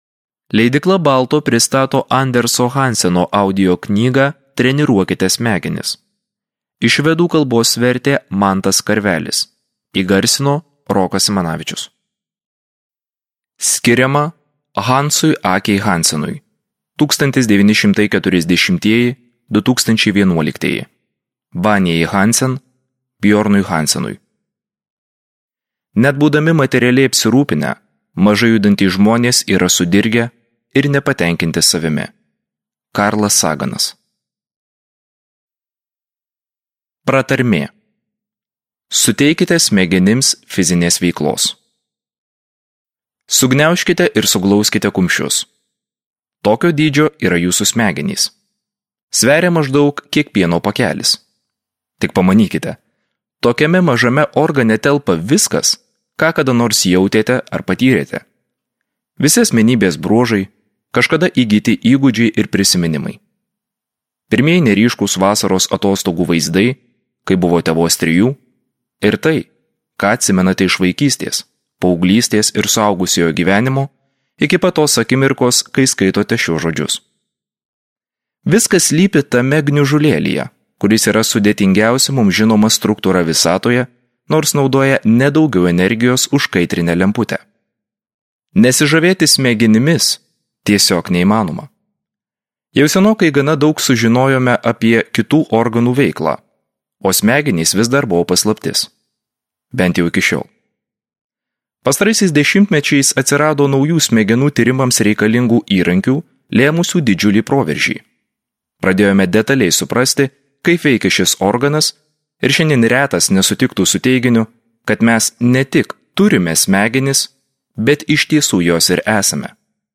Andersas Hansenas psichologinėje audioknygoje